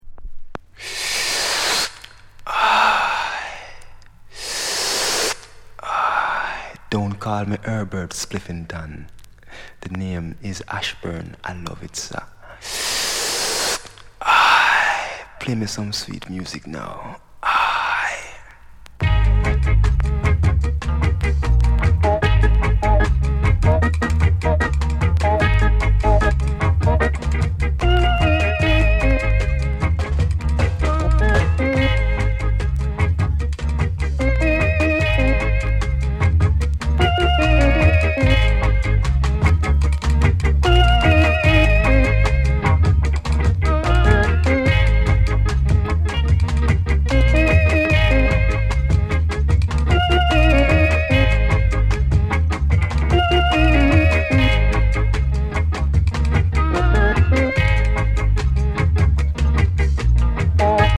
NICE EARLY REGGAE INST